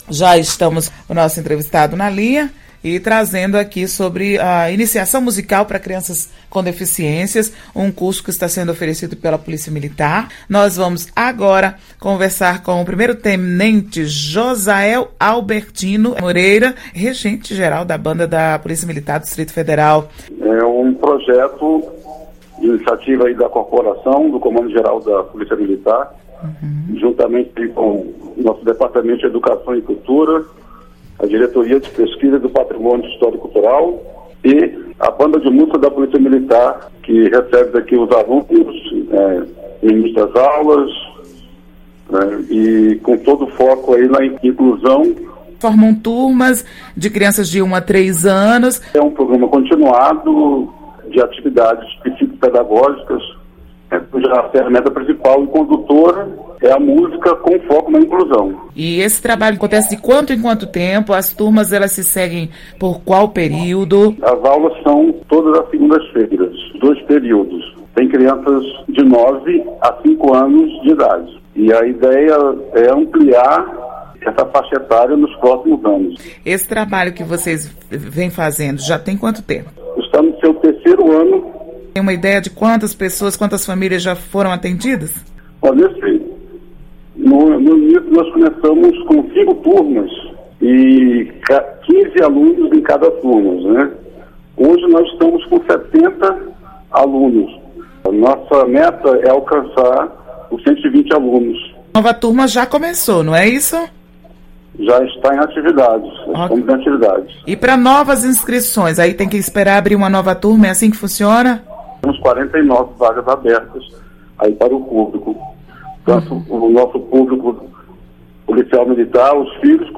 Entrevista: Conheça o curso de Musicalização da PMDF para crianças com deficiência